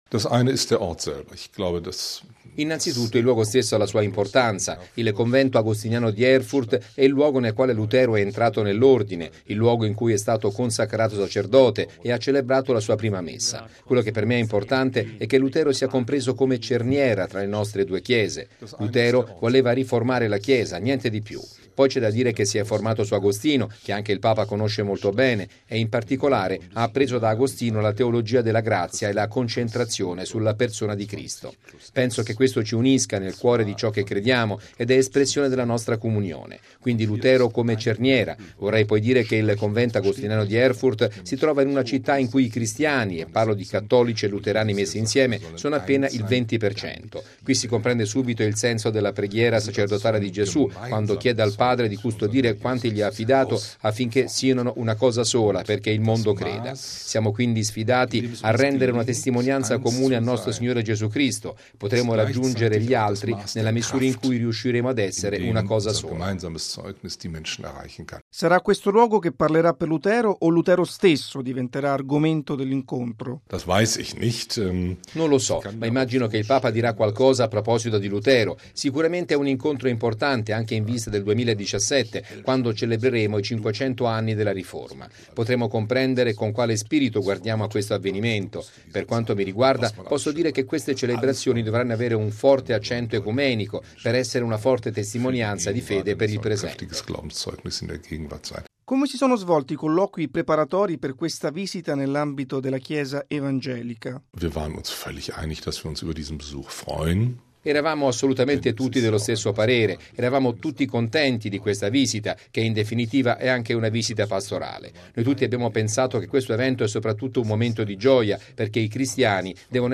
Sull’importanza di questo appuntamento, ascoltiamo il pastore Nikolaus Schneider, presidente del Consiglio della Chiesa evangelica tedesca, intervistato dalla Radio della diocesi di Colonia: